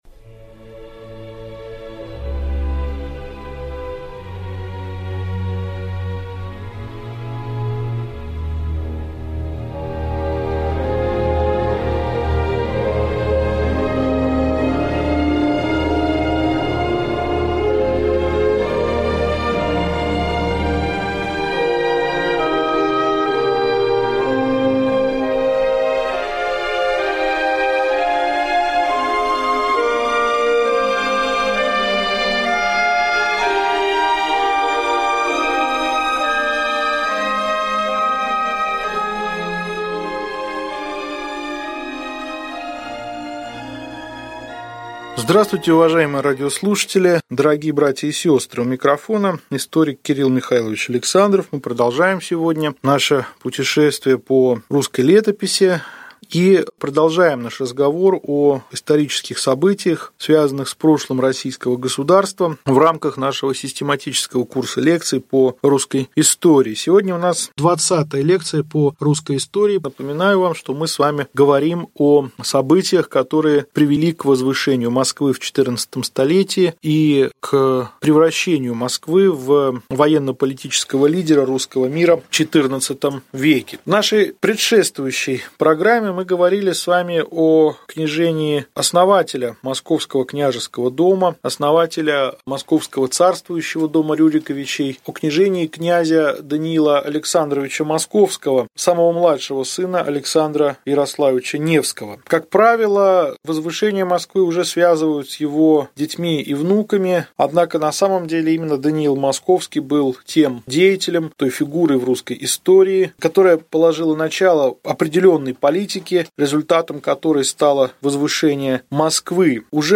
Аудиокнига Лекция 20. Кн. Иван Калита. Борьба Москвы с Тверью. Конец Владимирского периода | Библиотека аудиокниг